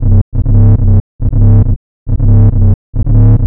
BL 138-BPM C#.wav